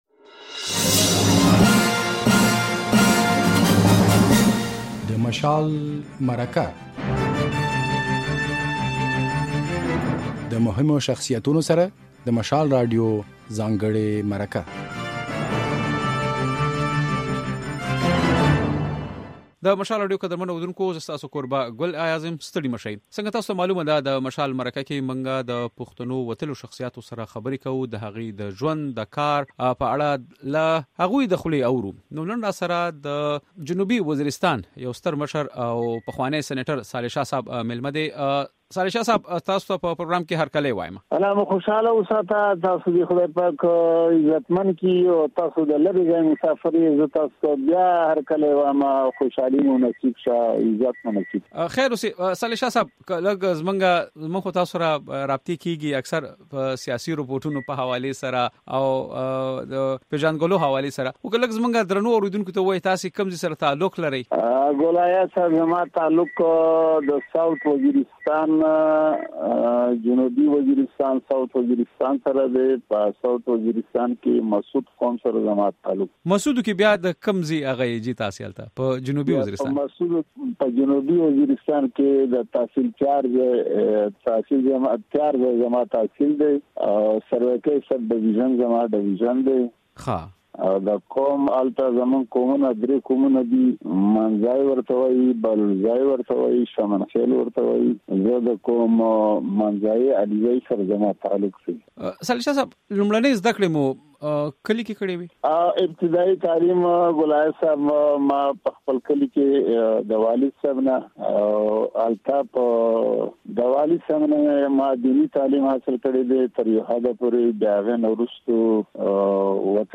د مشال مرکه کې مو د قبايلي ضلعې جنوبي وزيرستان پخوانی سېنېټر صالح شاه مېلمه دی.